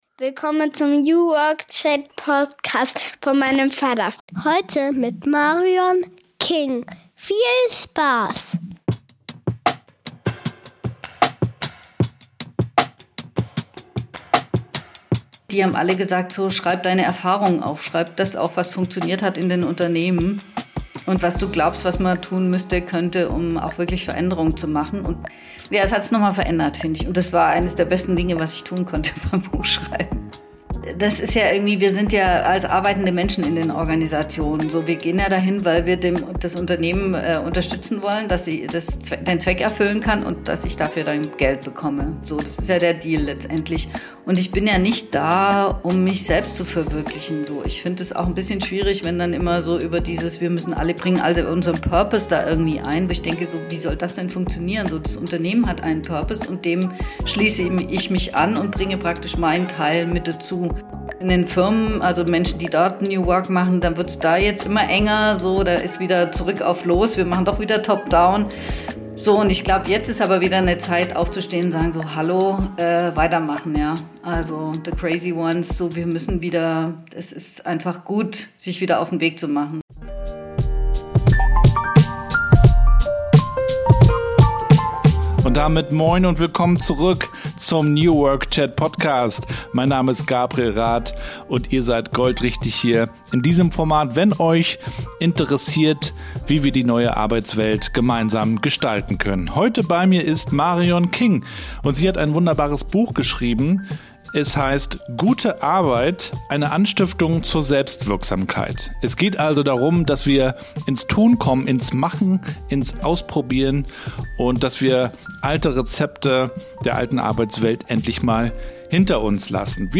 In meinem Podcast „New Work Chat“ interviewe ich spannende Köpfe rund um die Themen New Work, Kultur und Transformation. Wie finden wir eine Arbeit, die uns erfüllt und einen Unterschied macht?